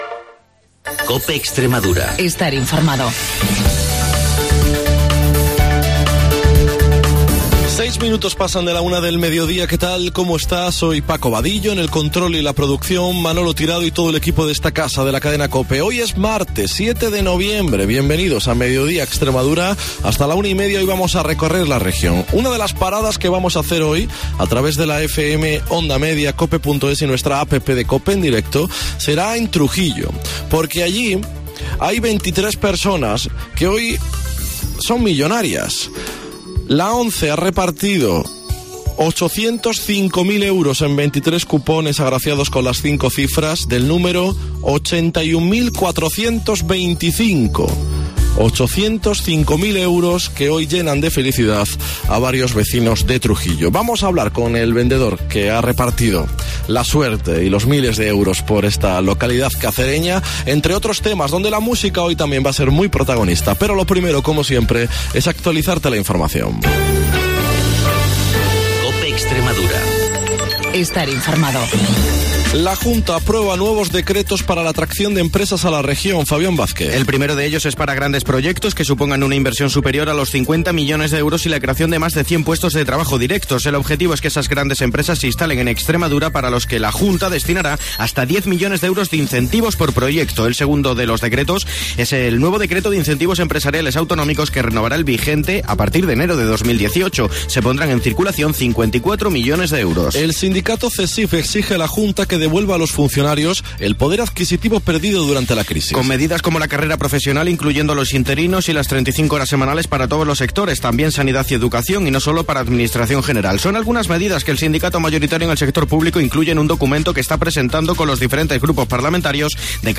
El programa líder de la radio extremeña